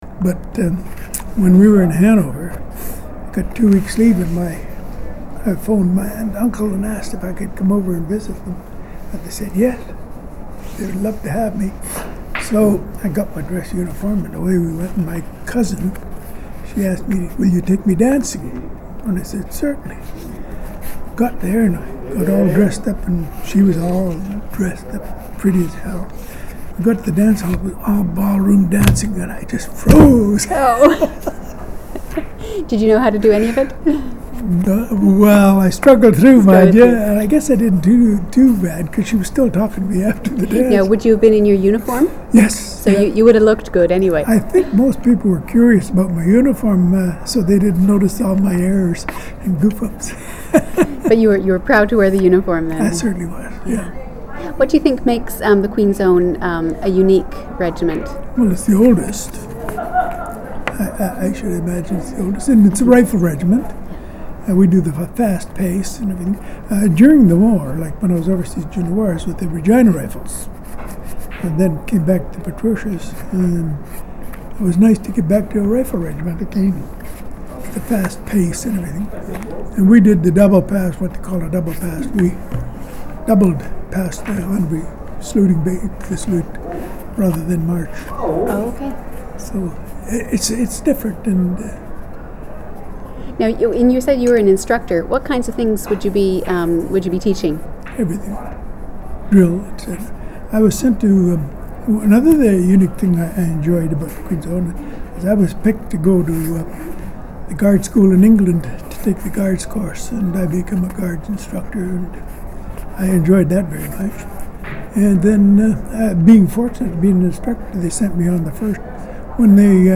• Interview took place during the Queen's Own Rifles of Canada Vancouver Island Branch 150th Anniversary Celebration.
• Canadian Military Oral History Collection